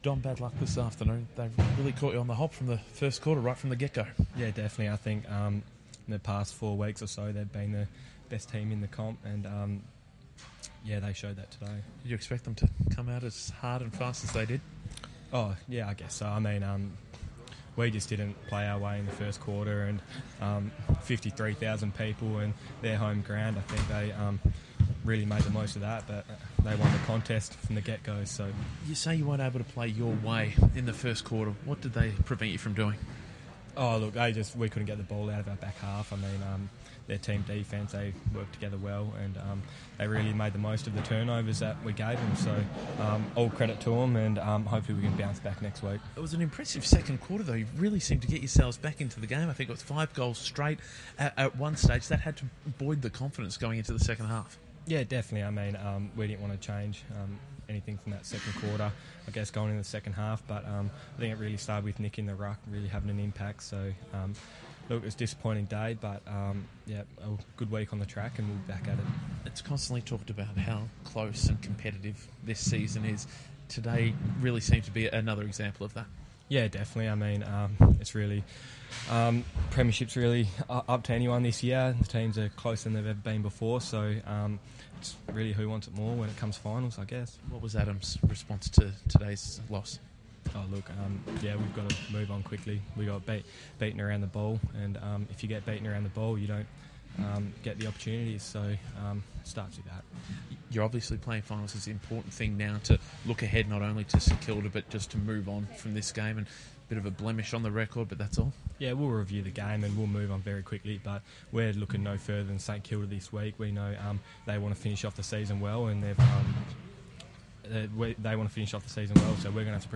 A somber Sheed speaks to 6PR after the Eagles 57-point loss